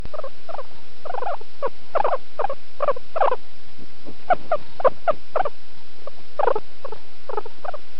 • A bubbling or purring sound is made when the guinea pig enjoys itself, such as when petting and holding.
Guinea_Pig_Happy.ogg.mp3